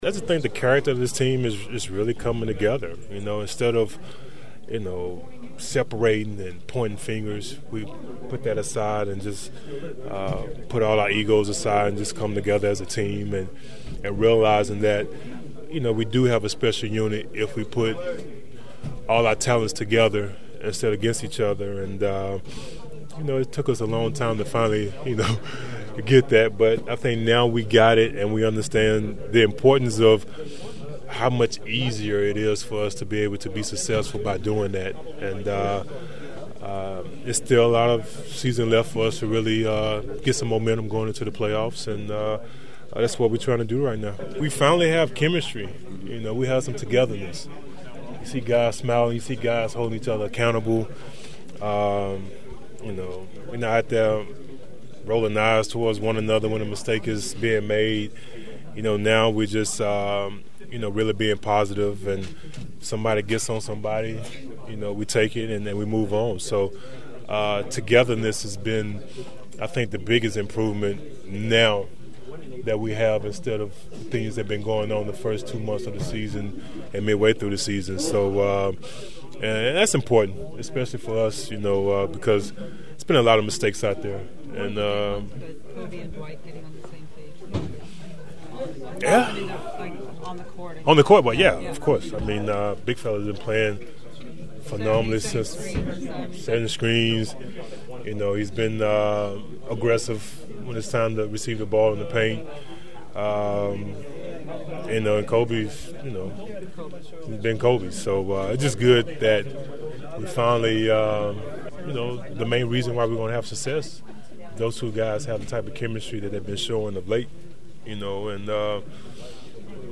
Either way the Lakers were all smiles in the postgame locker room and the following sound is for you to digest as they’re now 2 games over .500 for the first time this season before hitting the road for the next 3 games starting in Orlando on Tuesday night.